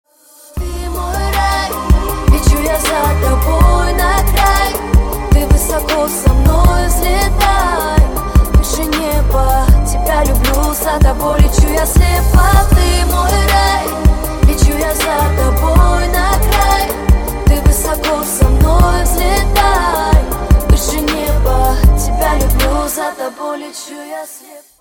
поп
красивые
женский вокал
грустные